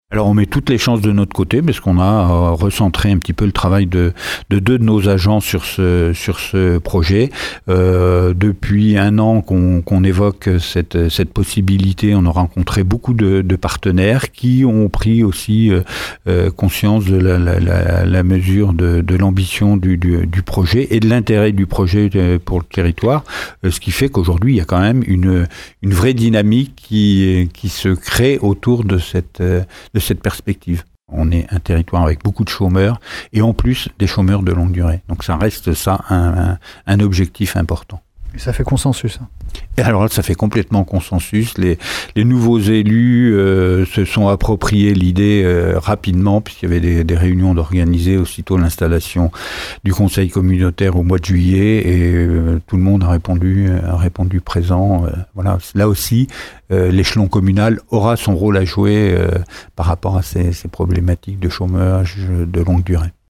On écoute Jean Gorioux, président de la CdC Aunis Sud :